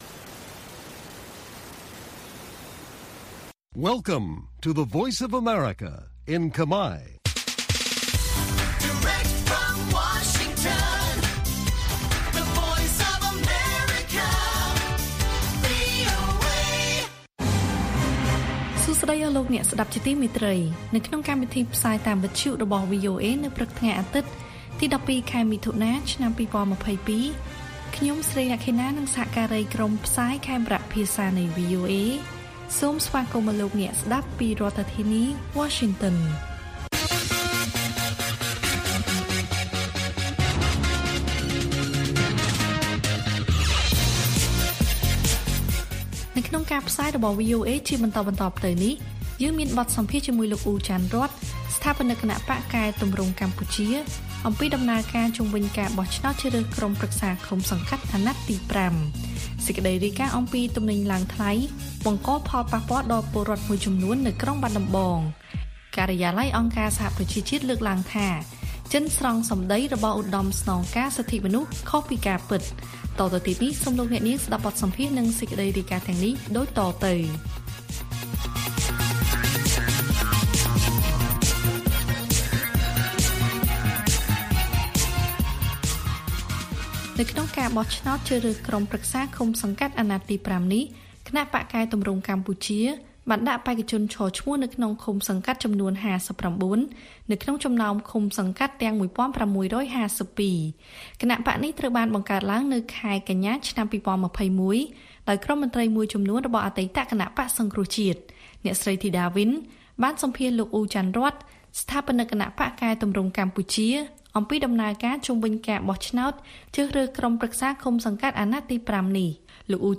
ព័ត៌មានពេលព្រឹក ១២ មិថុនា៖ បទសម្ភាសន៍ជាមួយលោក អ៊ូ ច័ន្ទរ័ត្ន ជុំវិញដំណើរការបោះឆ្នោតជ្រើសរើសក្រុមប្រឹក្សាឃុំសង្កាត់